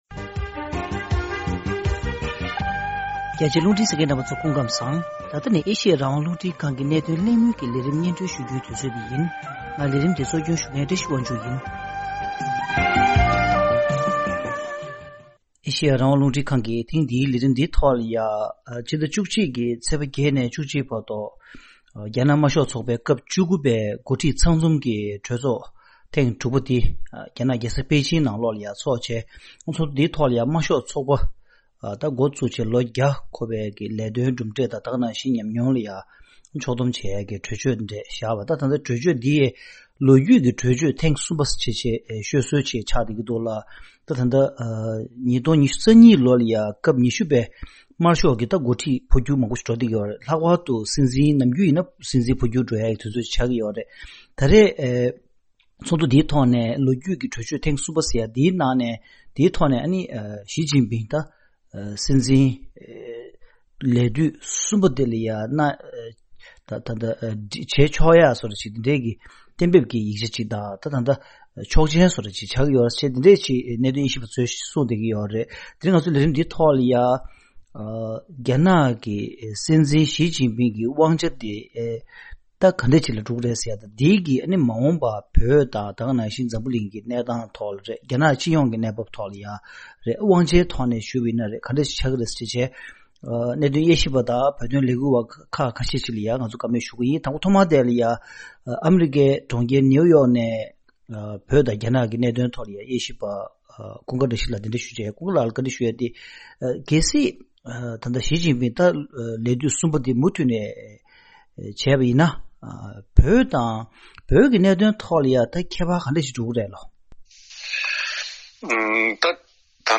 ཐེངས་འདིའི་གནད་དོན་གླེང་མོལ་གྱི་ལས་རིམ་ནང་། བོད་གནས་ཉམས་ཞིབ་པ་དང་། བོད་དོན་ལས་འགུལ་བ། ཆབ་སྲིད་བཙོན་ཟུར་བཅས་དང་ལྷན